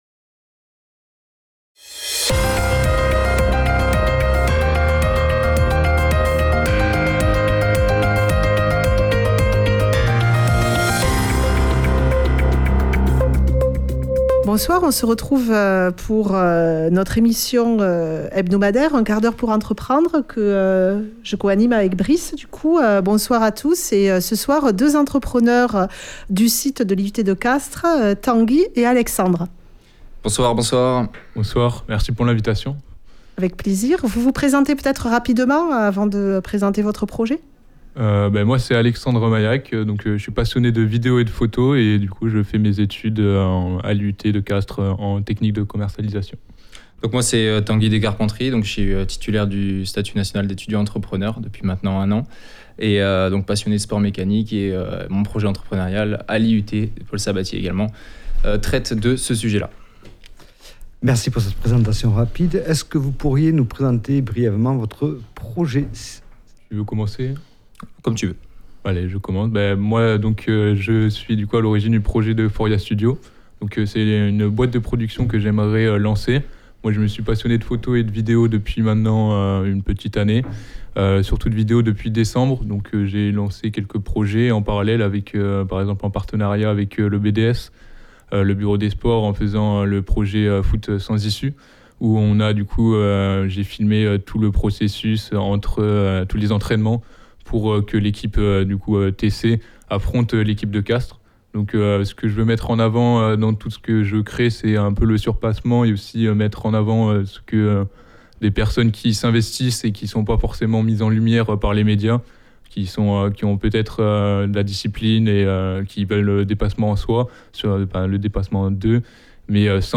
Reportages